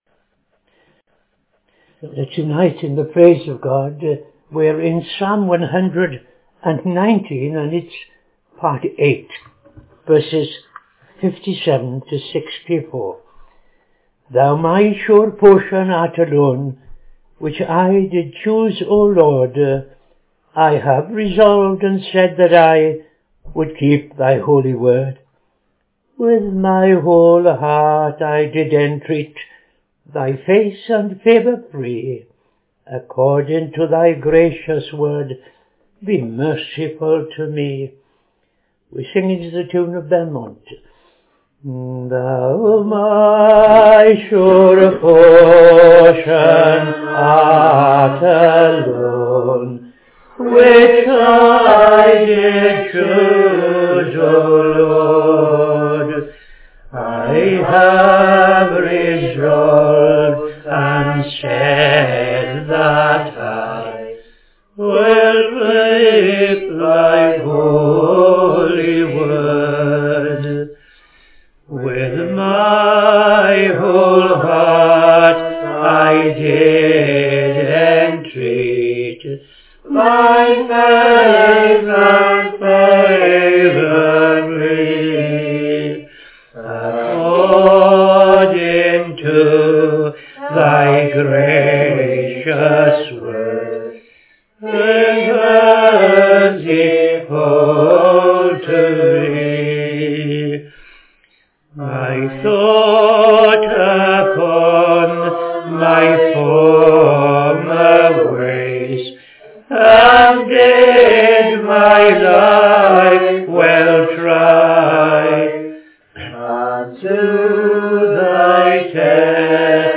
5.00 pm Evening Service Opening Prayer and O.T. Reading II Chronicles 3:1-17
Psalm 13:1-6 ‘How long wilt thou forget me, Lord?’ Tune Lloyd